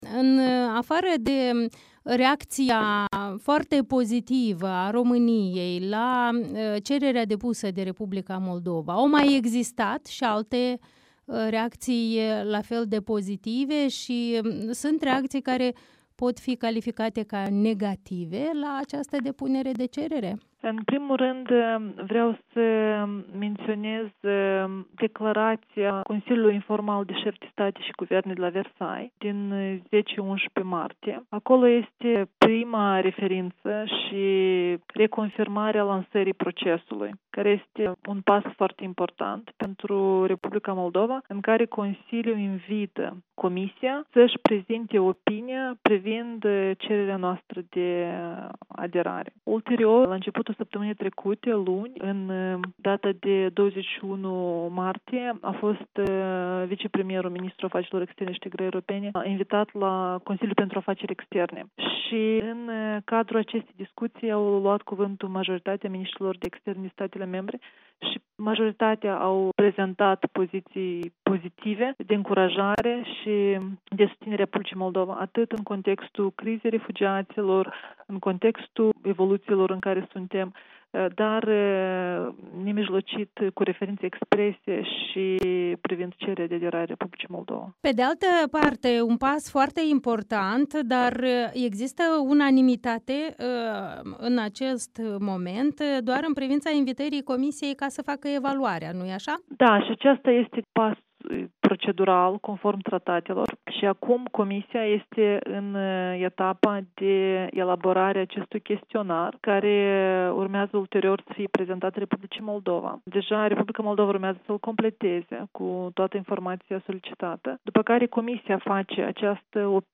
Ambasadoarea moldoveană la Bruxelles, Daniela Morari, este încurajată de primii pași politici ai Uniunii Europene în privința cererilor de aderare depuse la începutul lunii martie de Republica Moldova, Ucraina și Georgia, dar spune, într-un interviu acordat Europei Libere, că procesul birocratic...